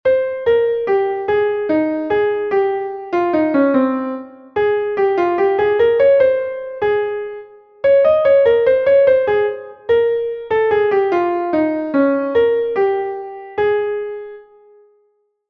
Entoación a capella